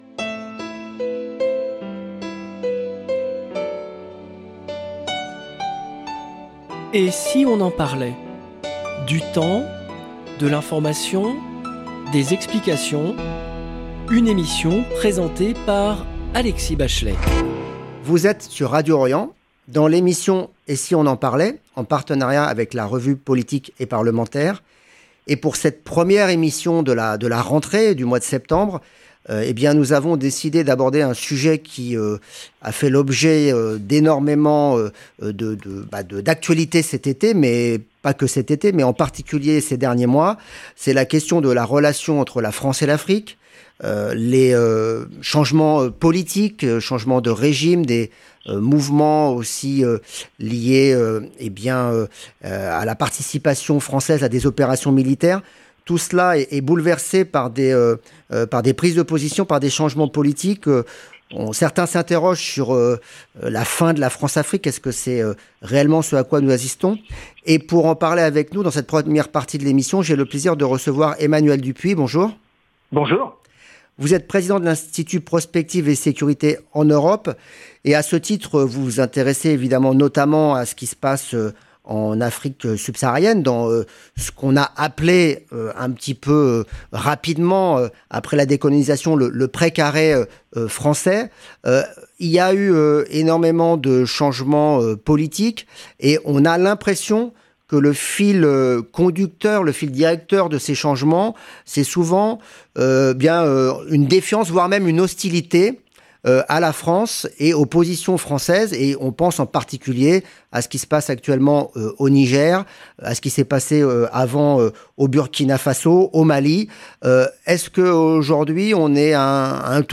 Emission diffusée le jeudi 7 septembre 2023